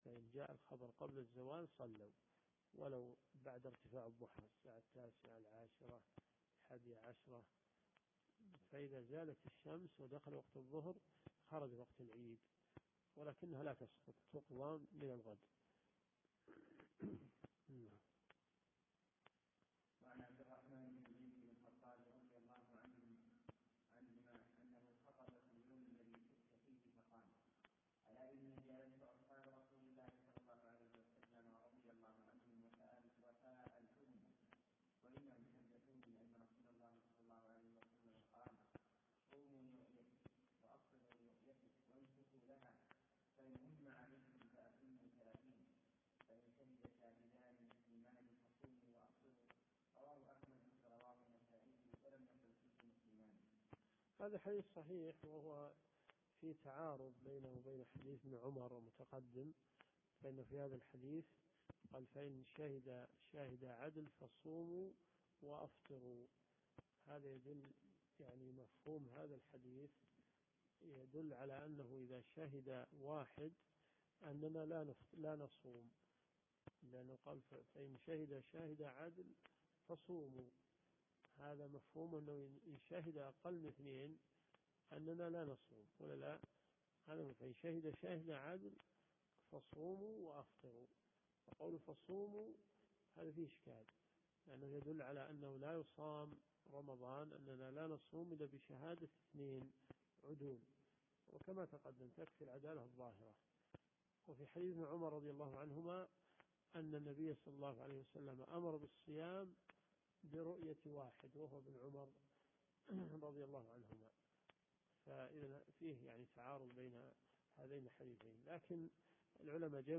من حديث 2099 إلى حديث 2113 . دورة صيفية في مسجد معاذ بن جبل .